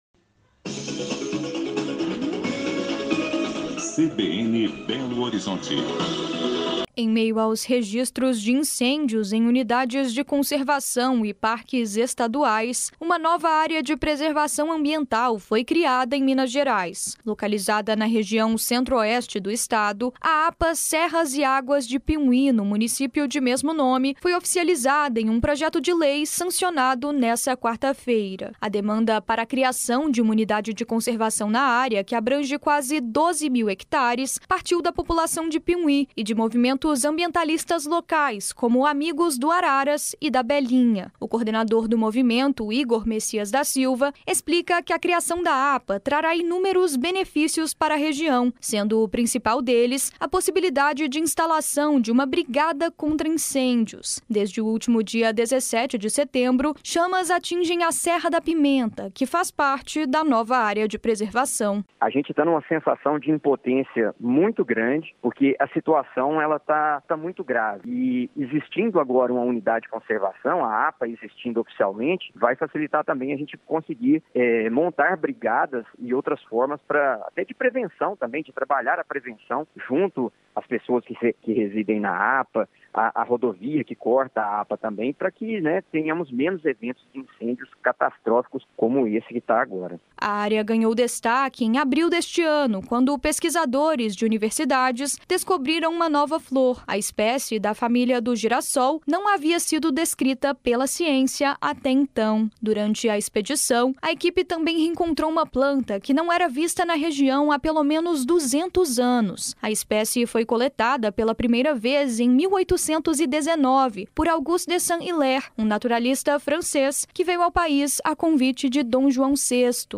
Reportagem veiculada na CBN BH sobre a criação da Área de Proteção Ambiental Municipal APA Serras e Águas de Piumhi